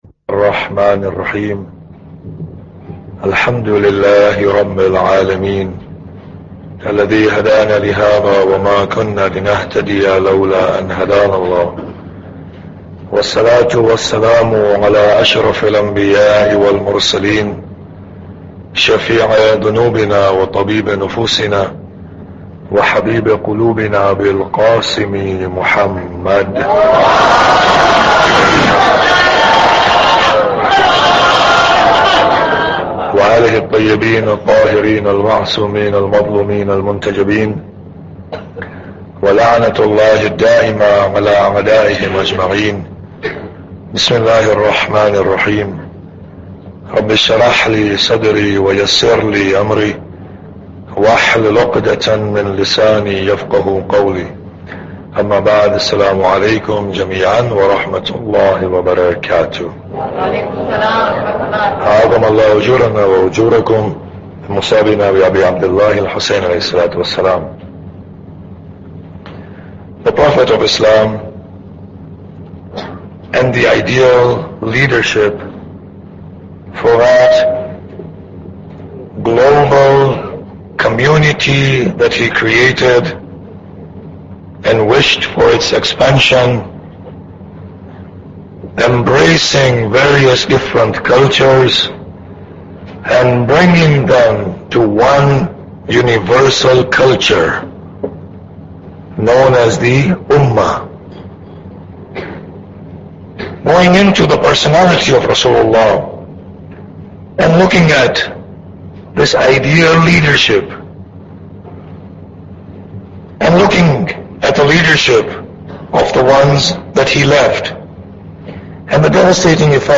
Muharram Lecture 6